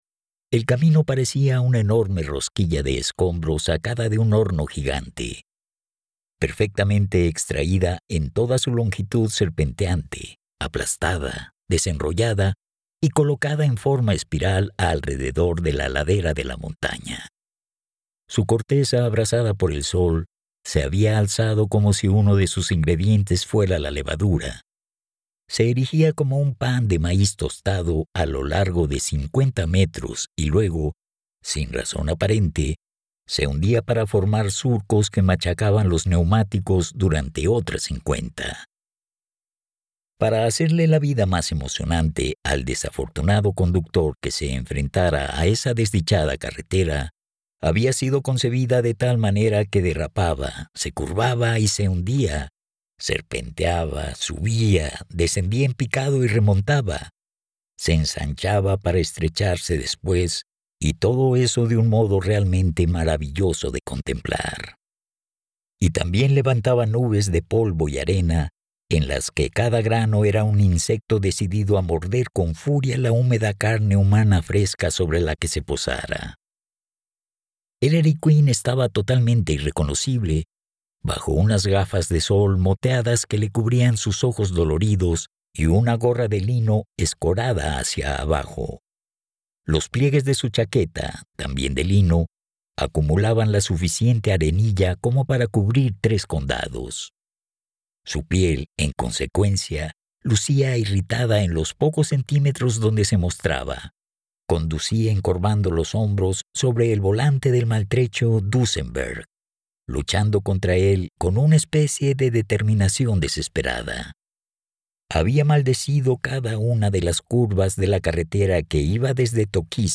Audiolibros Novela policíaca - Sonolibro